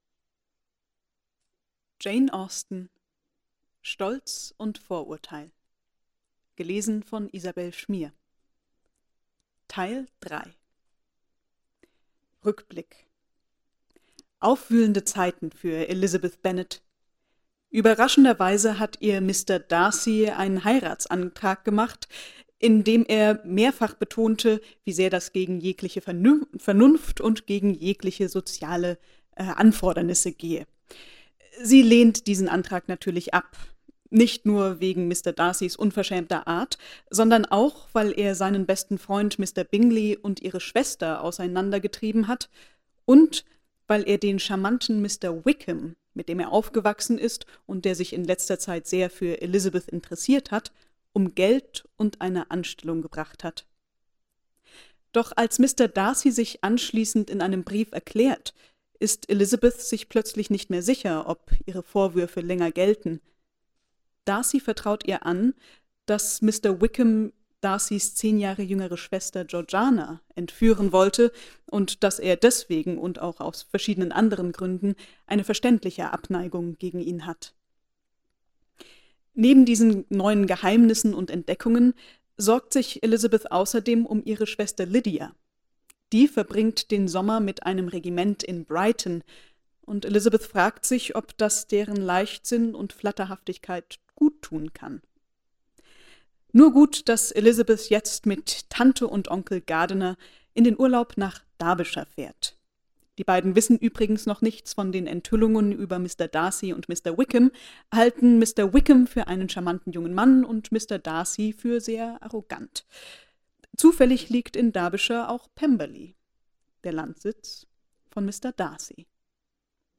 Vorlesen
jane-austen-auszuege-aus-stolz-und-vorurteil.mp3